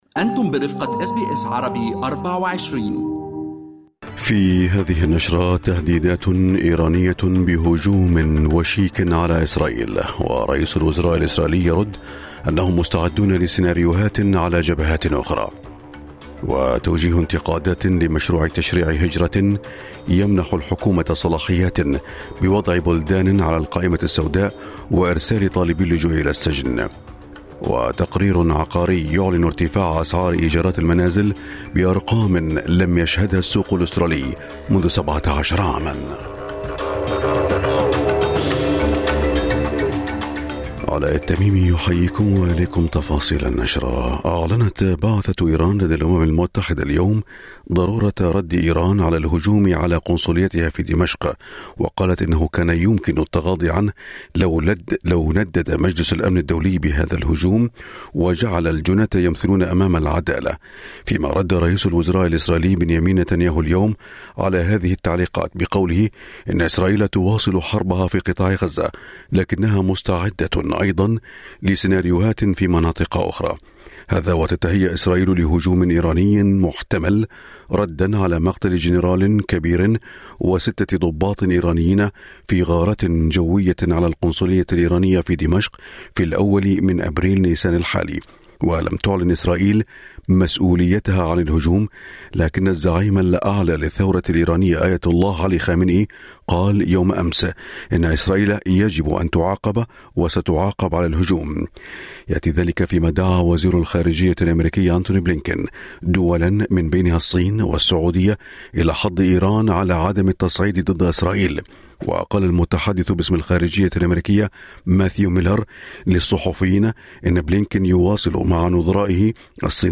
نشرة أخبار الصباح 12/4/2024